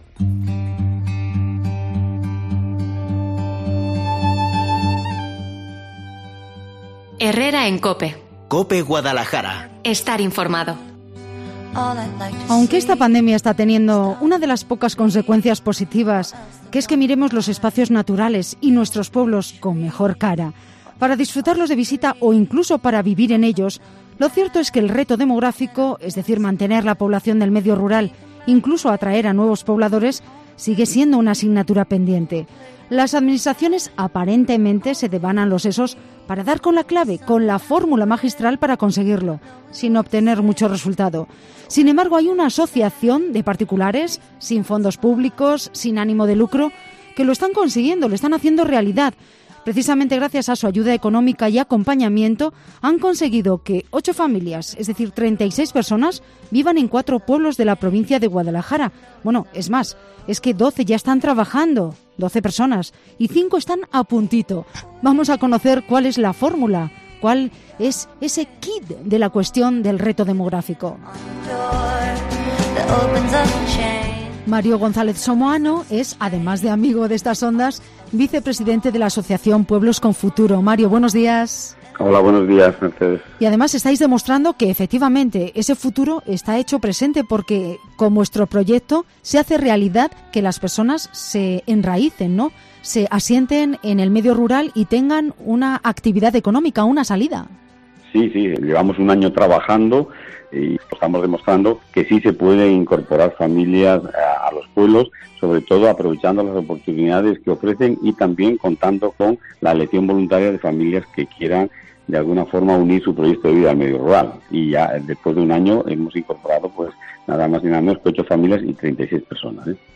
ha estado en Mediodía COPE Guadalajara para contarnos cuál es su fórmula magistral y efectiva contra el despoblamiento.